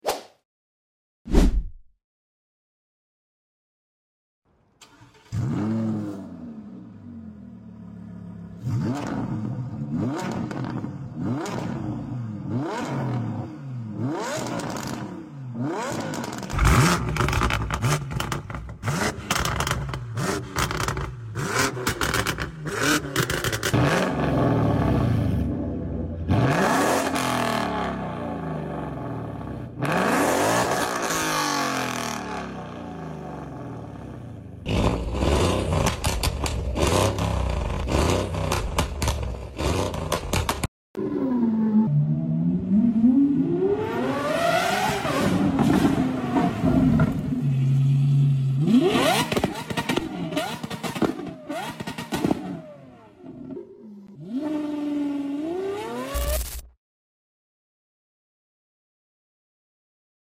Epic Car Sounds In Stunning Sound Effects Free Download